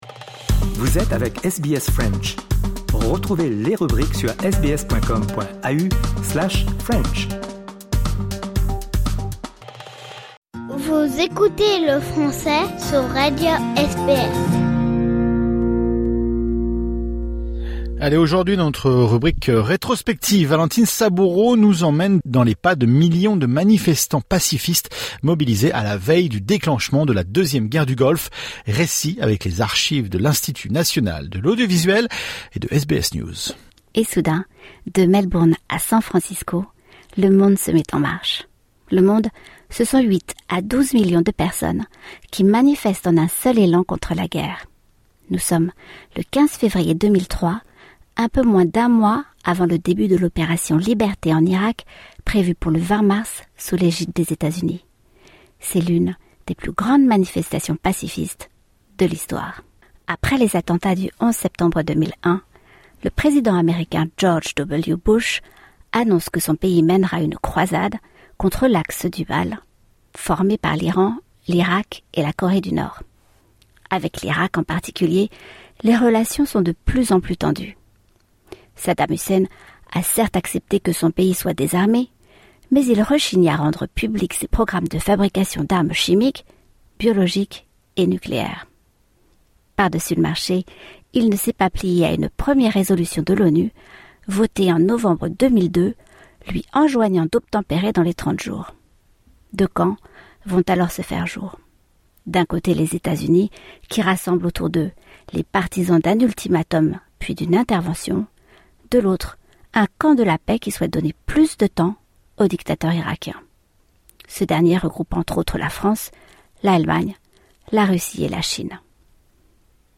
Récit, avec les archives de l’Institut national de l’audiovisuel et de SBS News.